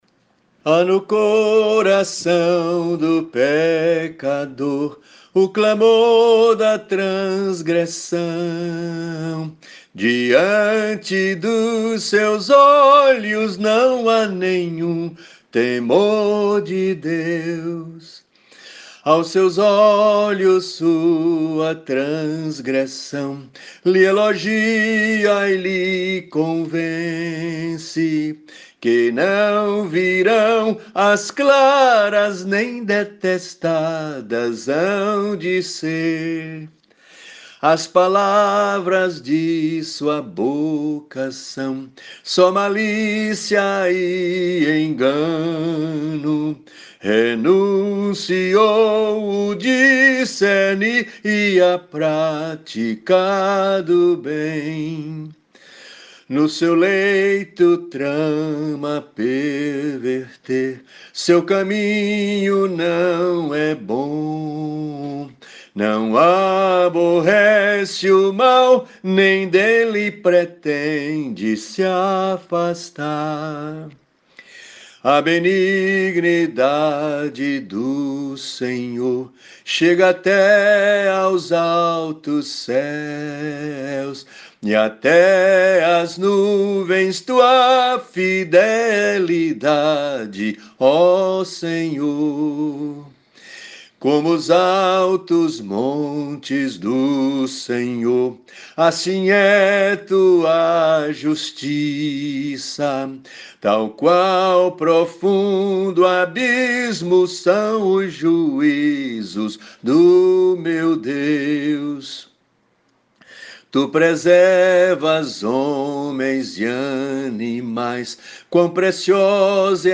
Métrica: 9 8. 7 8
salmo_36B_cantado.mp3